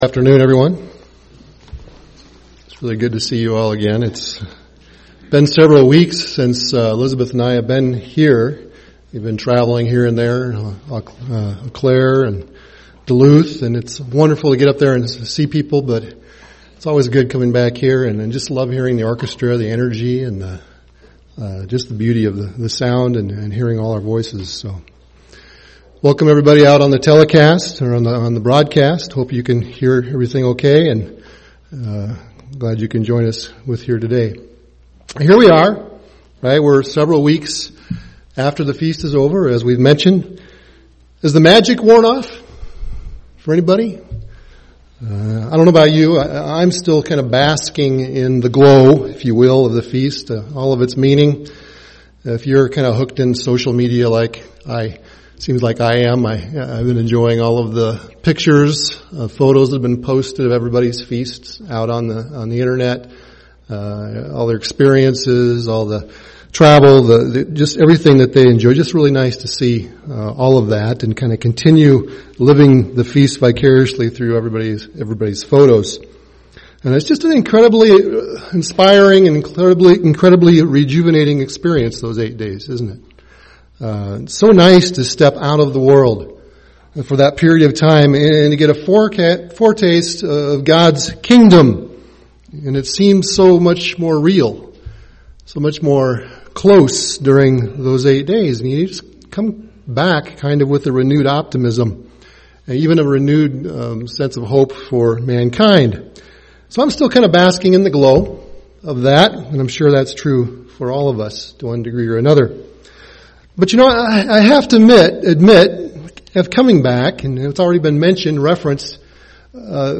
Given in Twin Cities, MN
UCG Sermon Elijah focused attention Studying the bible?